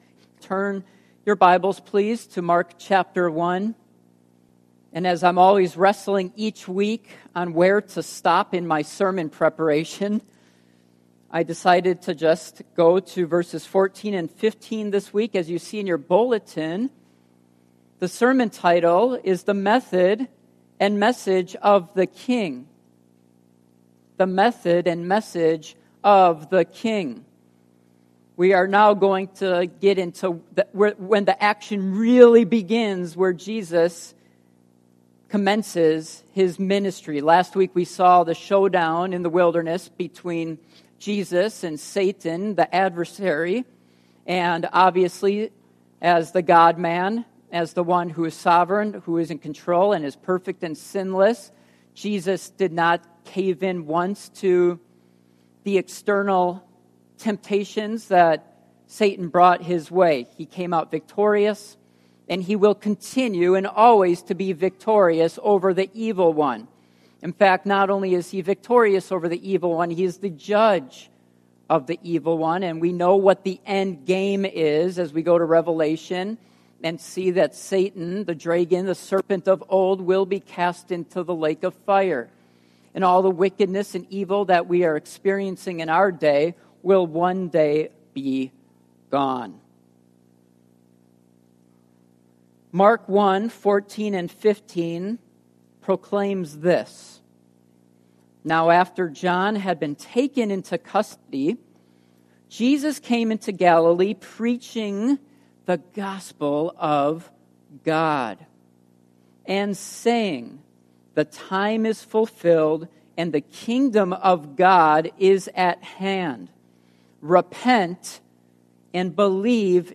Service Type: Morning Worship